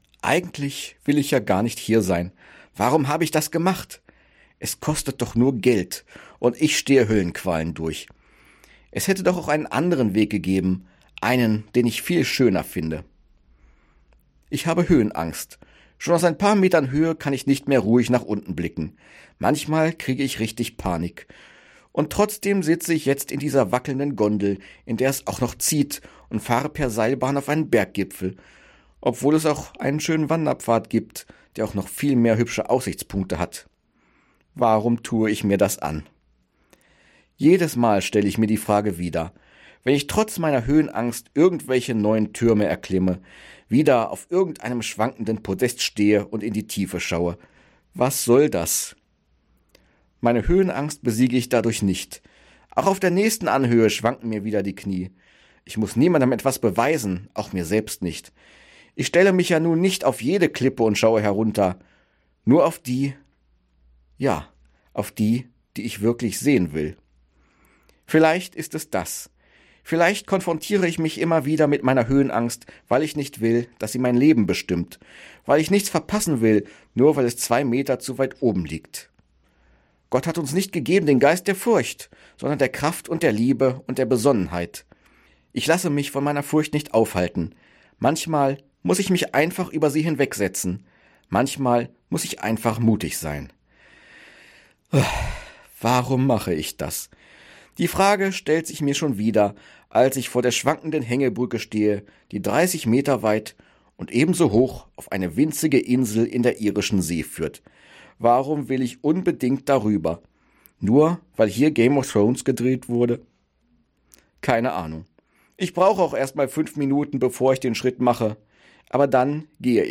Radioandacht vom 20. November